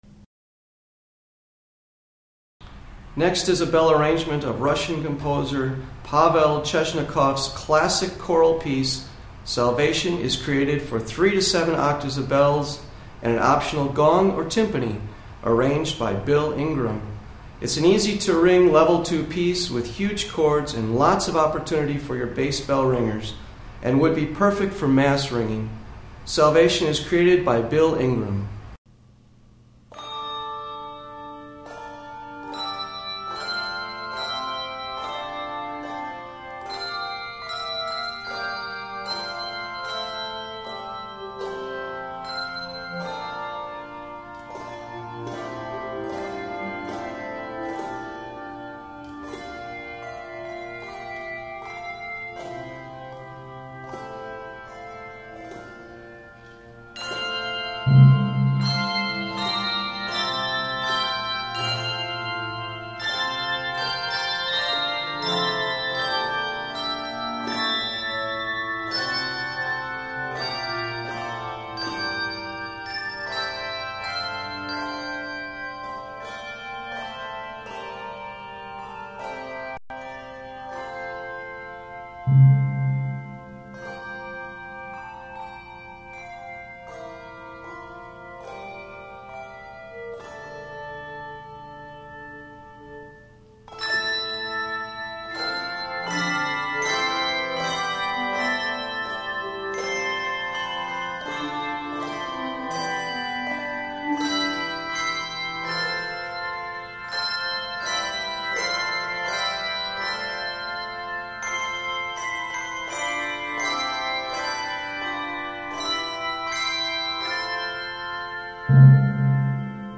Voicing: Handbells 3-7 Octave